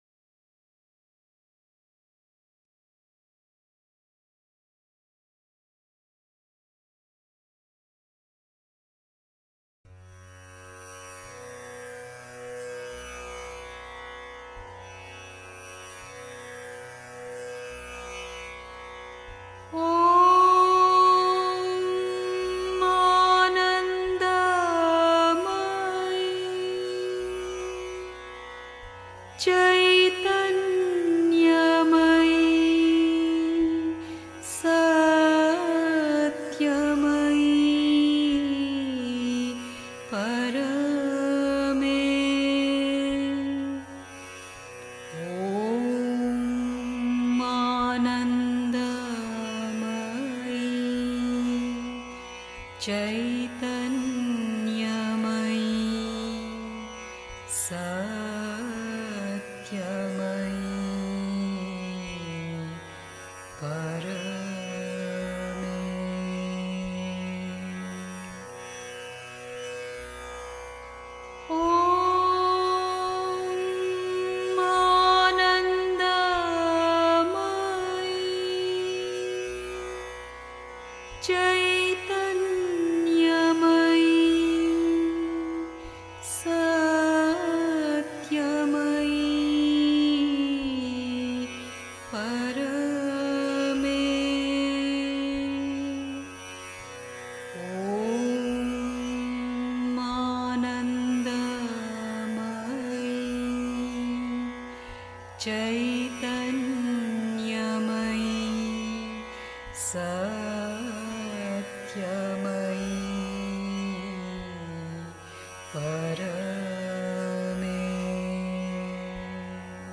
1. Einstimmung mit Musik. 2.